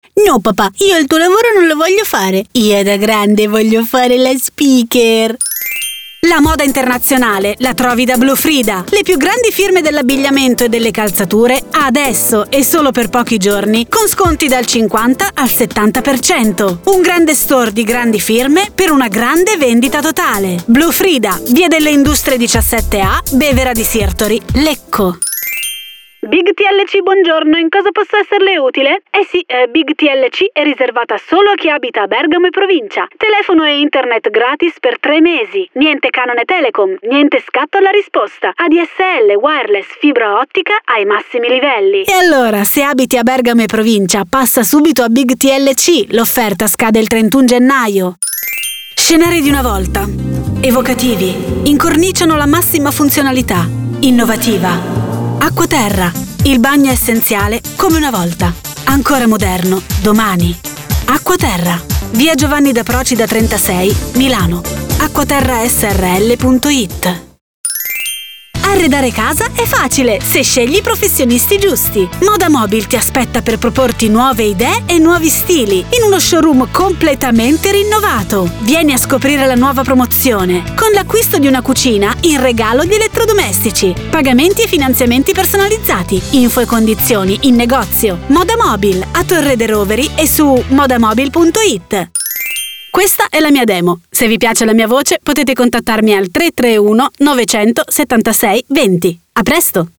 Speaker pubblicitaria con home studio
Sprechprobe: Werbung (Muttersprache):